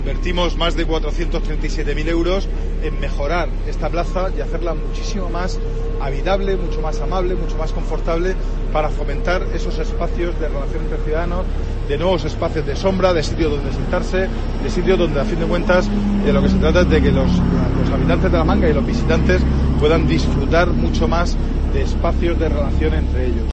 Consejero de Fomento sobre obras Plaza Bohemia